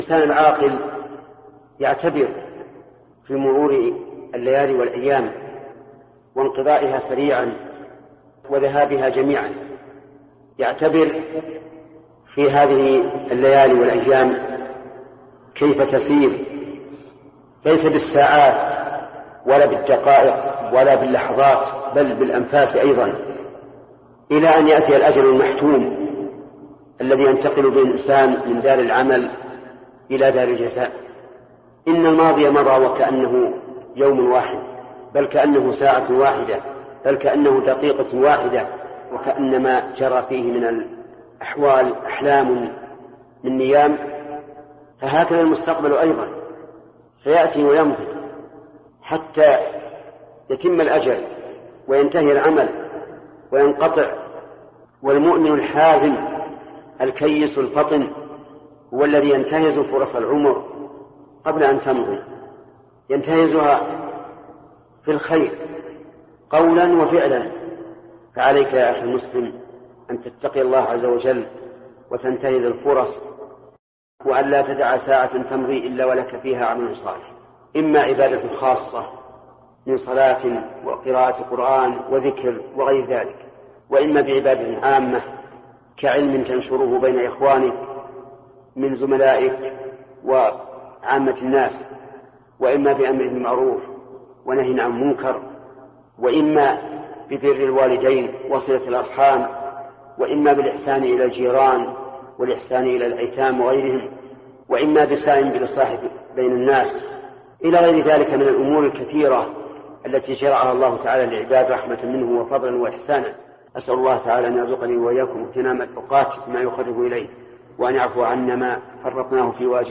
الإنسان العاقل يعتبر في مرور الليالي والأيام. التصنيف: نصائح ومواعظ